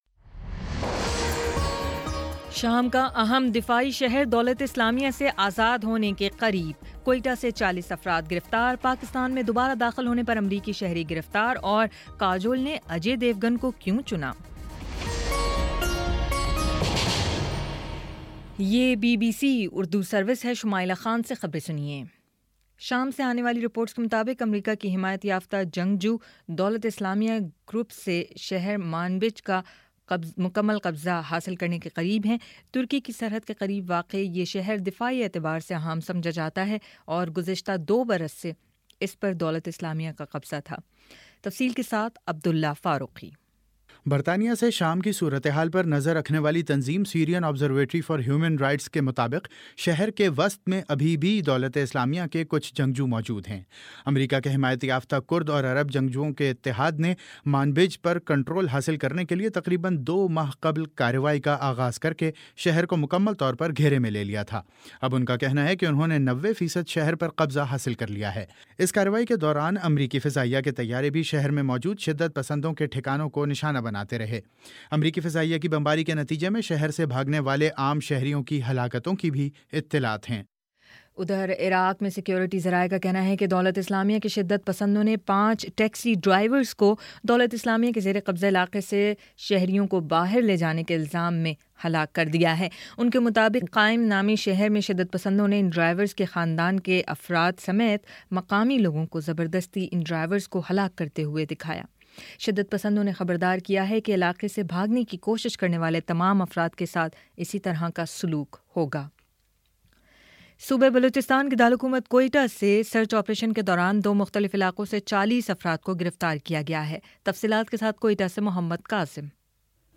اگست 06 : شام چھ بجے کا نیوز بُلیٹن